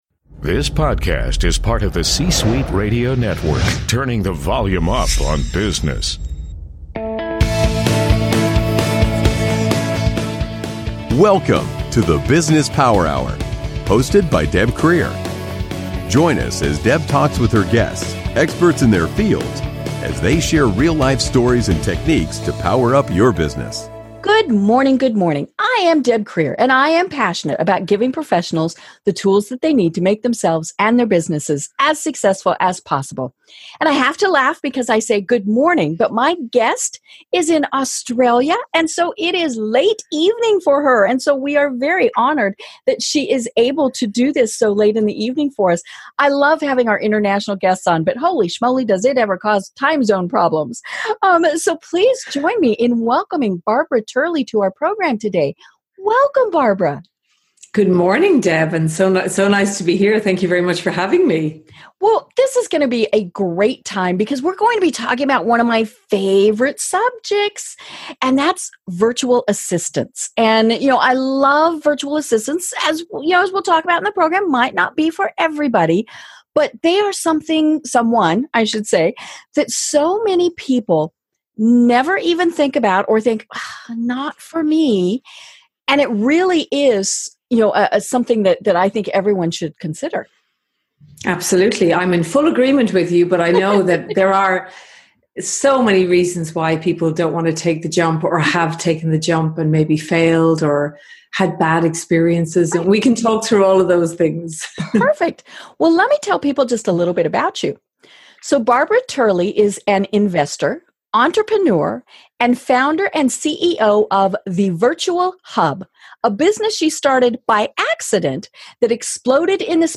Introduction and Guest Welcome